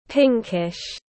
Màu phớt hồng tiếng anh gọi là pinkish, phiên âm tiếng anh đọc là /ˈpɪŋ.kɪʃ/.
Pinkish /ˈpɪŋ.kɪʃ/